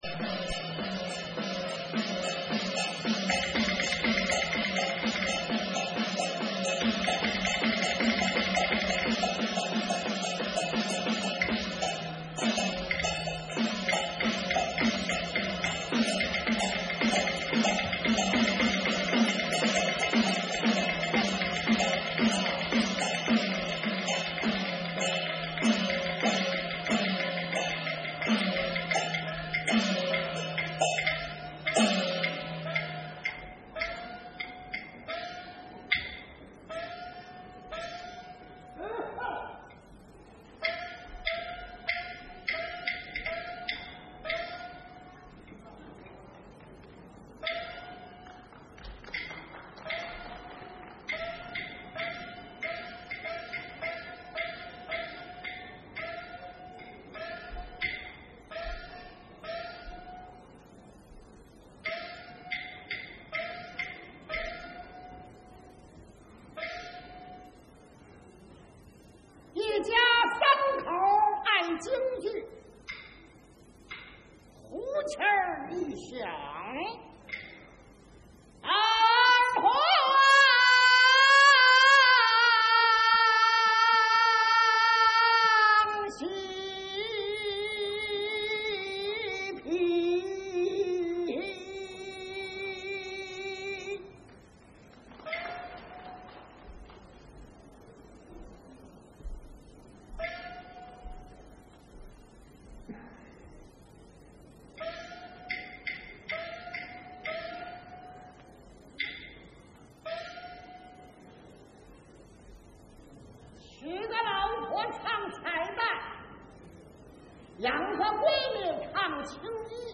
串唱京梆，并自拉自唱。
操琴
司鼓
京剧